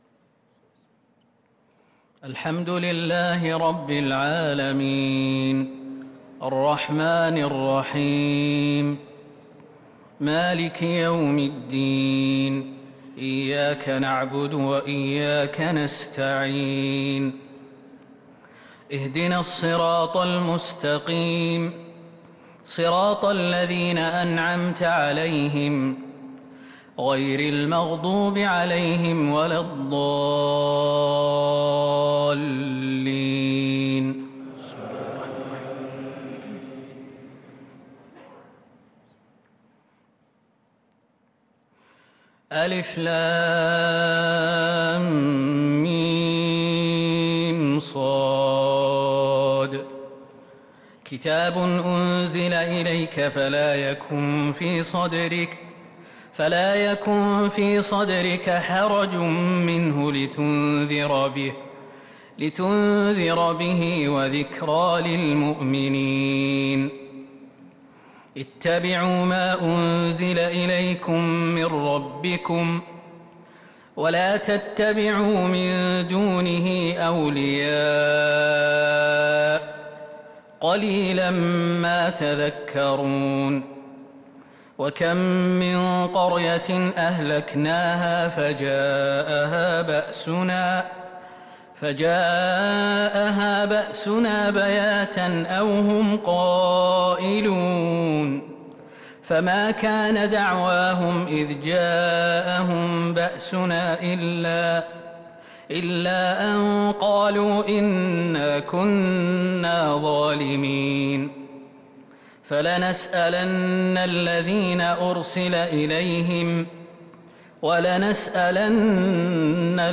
تراويح ليلة 11 رمضان 1443هـ من سورة الأعراف (1-79) |taraweeh 11st niqht ramadan Surah Al-A’raf 1443H > تراويح الحرم النبوي عام 1443 🕌 > التراويح - تلاوات الحرمين